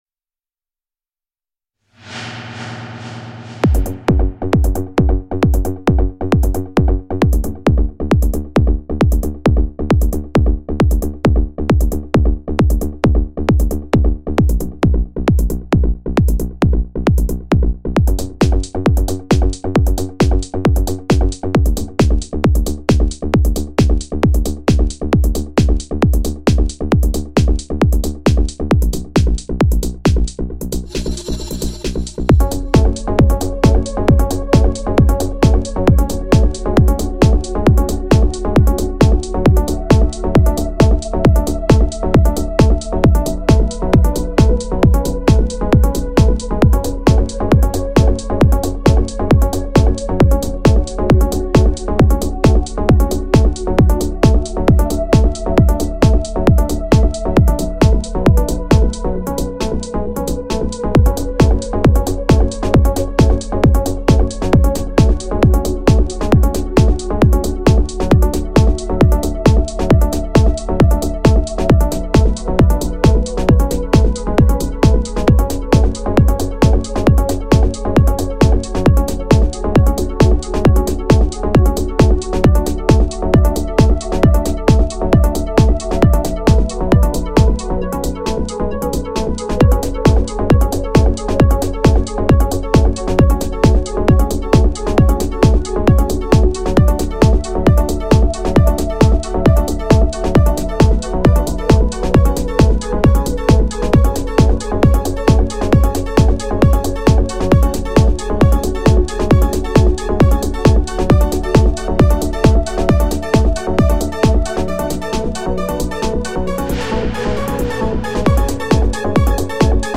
تقریبا بطور اتفاقی در اثر بالا پایین کردن پیچهای میکسر با یه ملودی ساده و کمی ذوق و خلاقیت ساخته شد!
شمسایی: عید غدیر روز همبستگی است؛ به اهل سنت احترام می‌گذارم برچسب‌ها: trance FL Studio Strange .